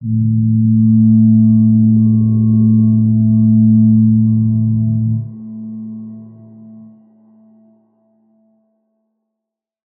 G_Crystal-A3-f.wav